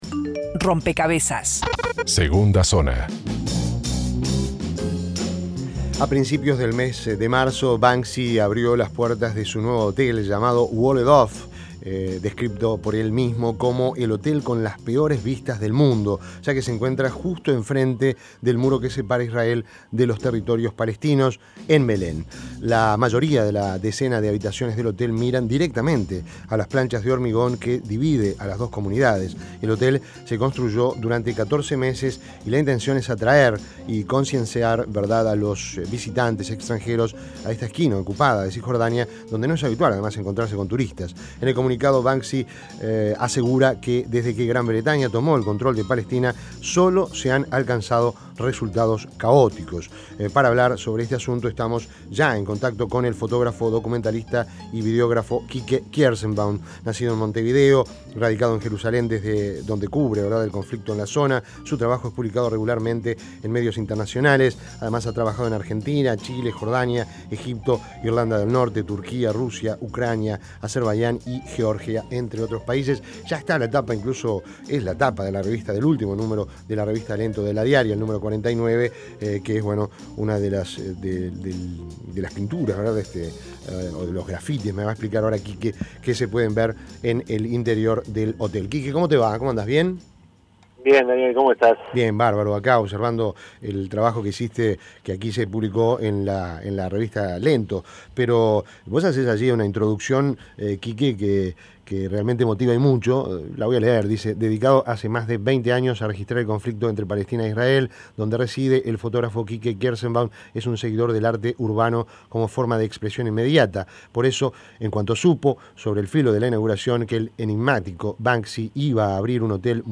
Entrevista en Rompkbzas Fuera de lugar Fuera de lugar Fuera de lugar.